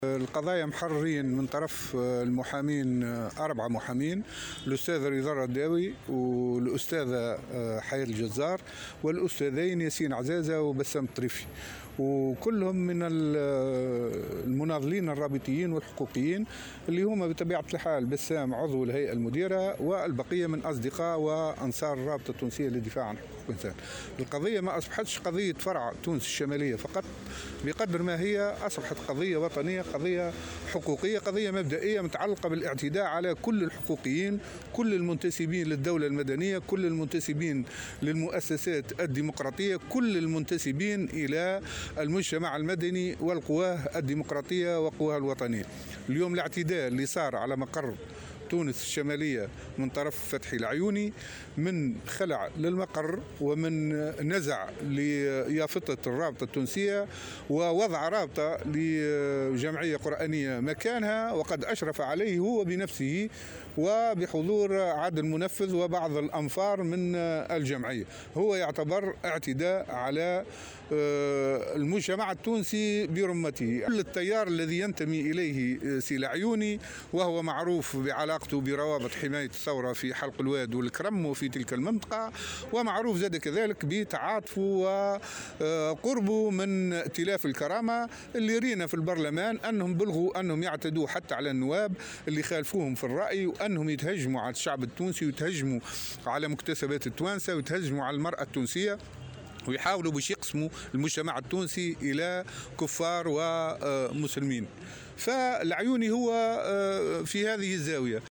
وأضاف في تصريح اليوم لمراسل "الجوهرة أف أم" على هامش ندوة صحفية عقدتها الرابطة اليوم، أن القضية أصبحت وطنية وحقوقية وأن الاعتداء على مقر الرابطة هو اعتداء على المجتمع التونسي برمته، وفق قوله.